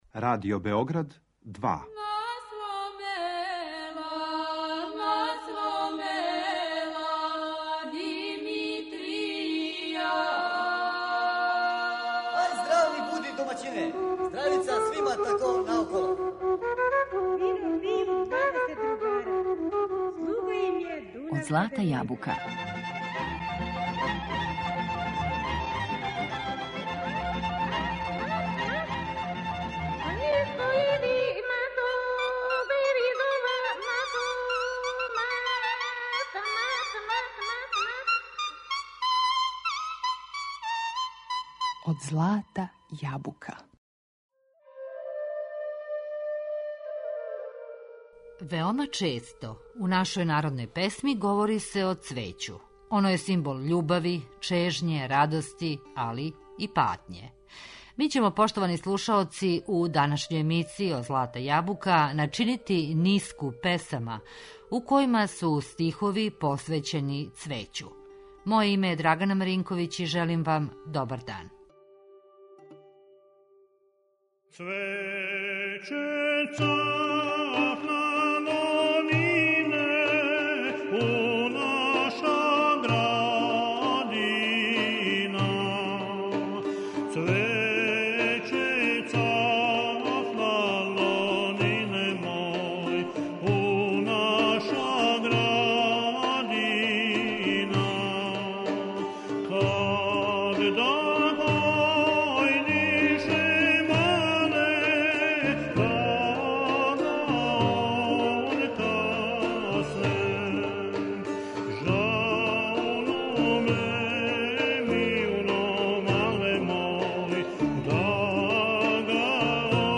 Песме посвећене цвећу